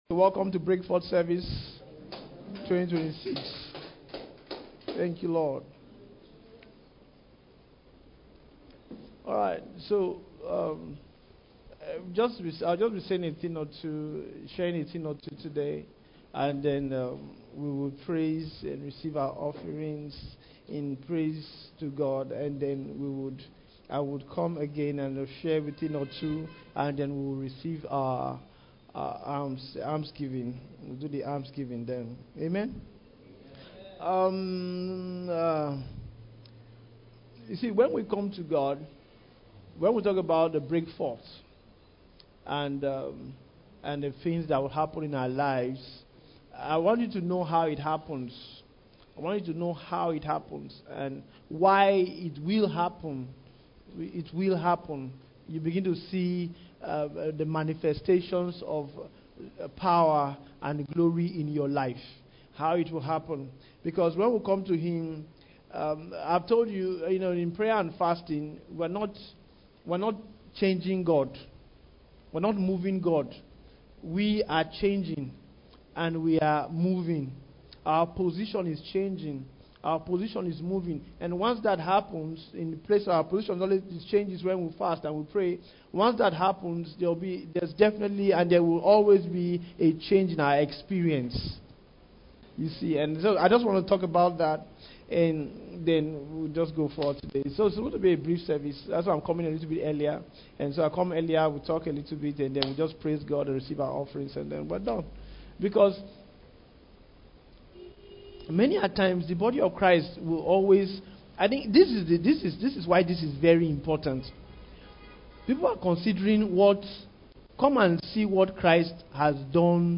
BreakForth - Special Praise and Almsgiving Service - Word Pasture